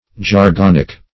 Jargonic \Jar*gon"ic\, a. Of or pertaining to the mineral jargon.
jargonic.mp3